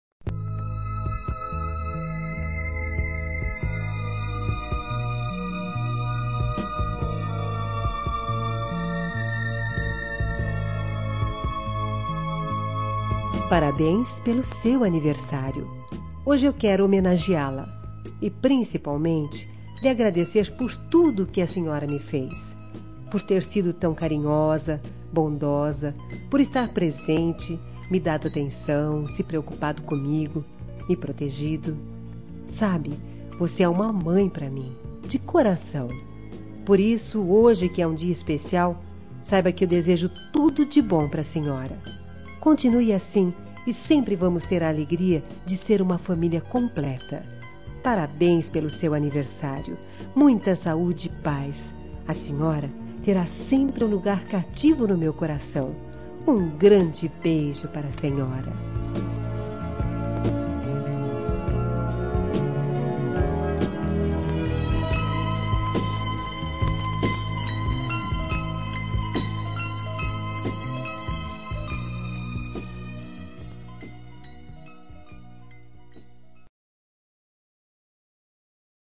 Telemensagem Aniversário de Mãe – Voz Feminina – Cód: 1409 – Madrasta